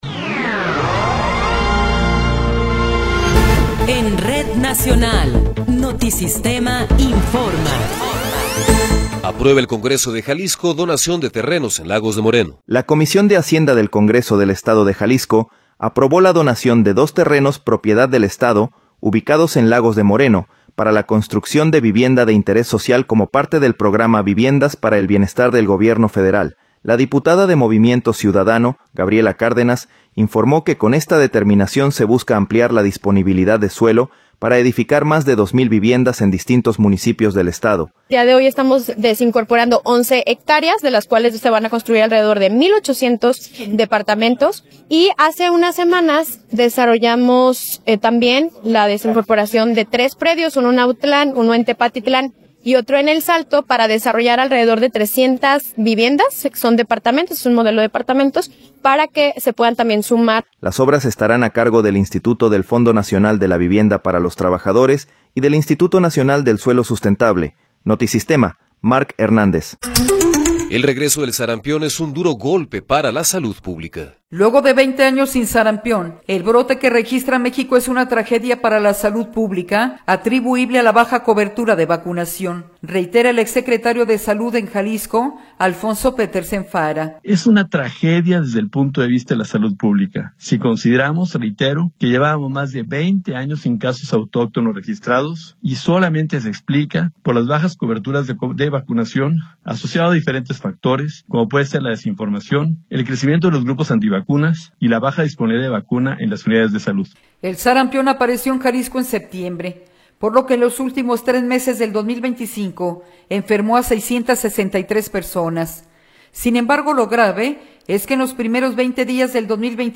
Noticiero 13 hrs. – 21 de Enero de 2026